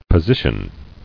[po·si·tion]